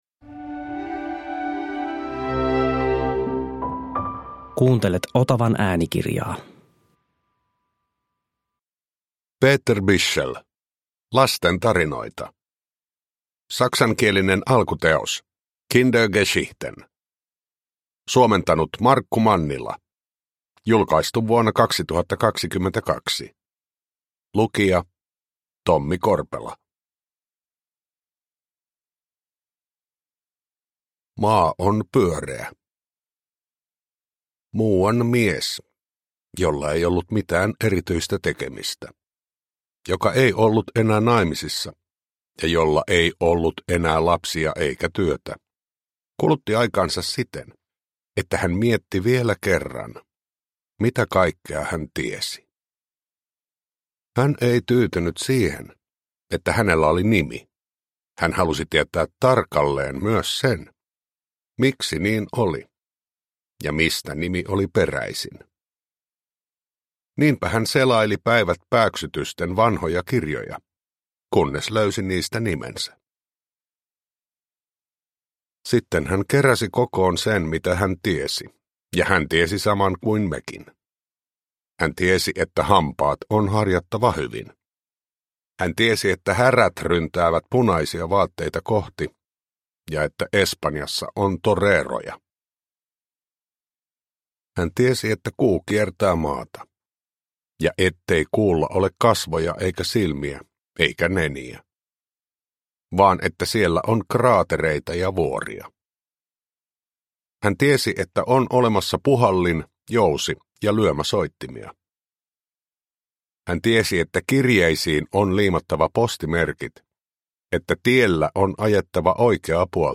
Lastentarinoita – Ljudbok – Laddas ner
Uppläsare: Tommi Korpela